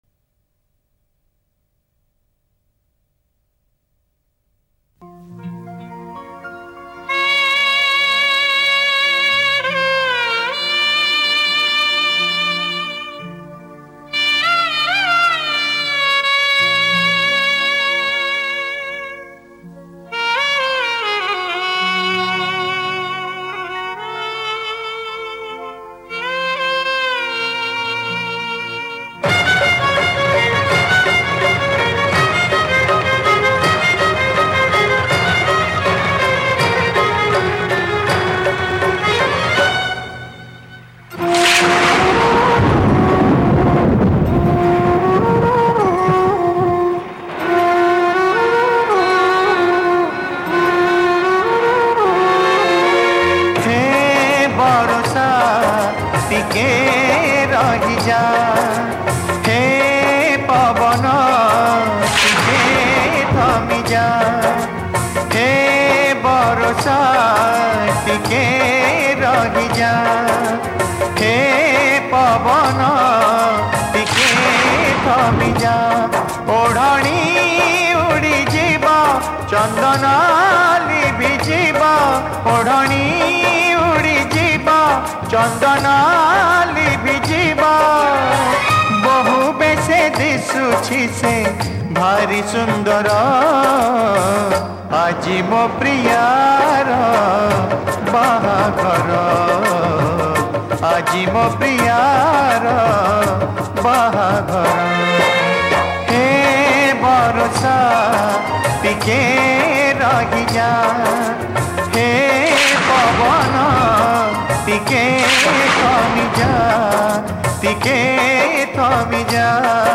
Odia Old Demand Album Sad Songs